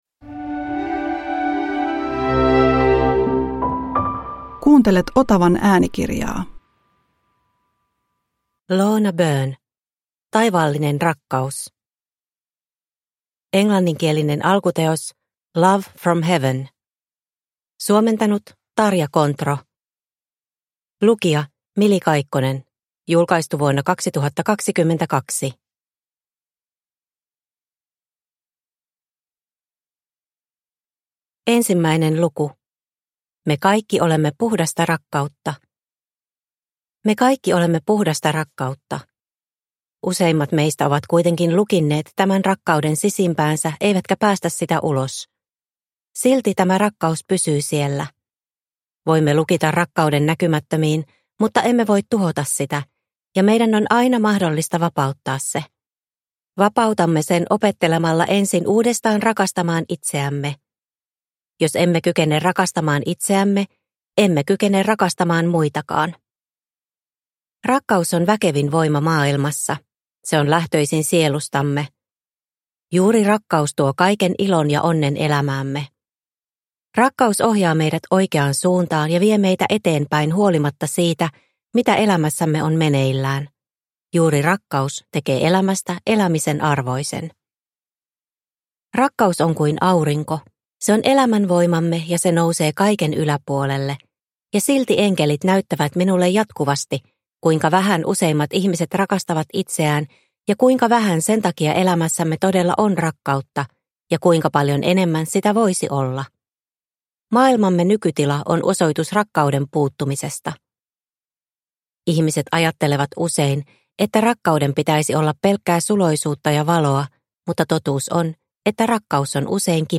Taivaallinen rakkaus – Ljudbok – Laddas ner